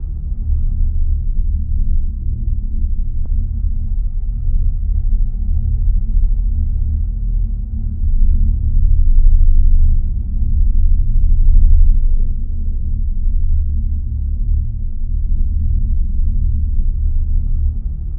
ambience
deep.wav